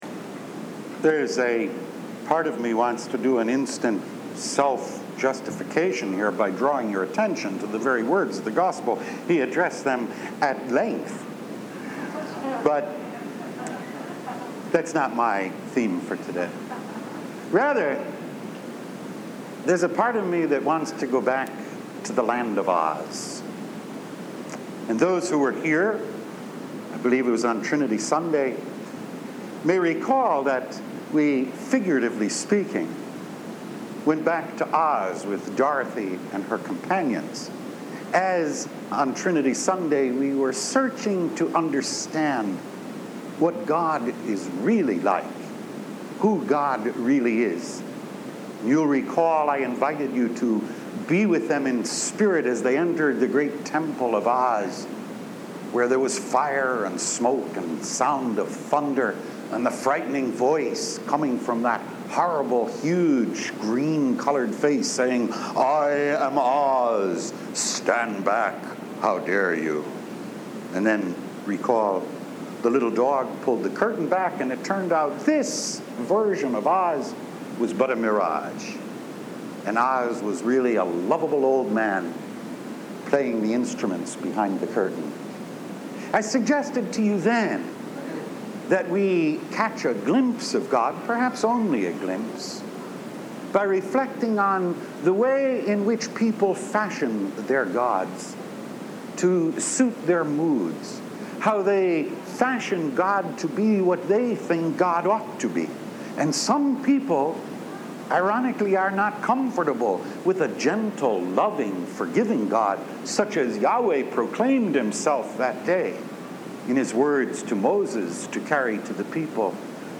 Originally delivered on July 15, 1990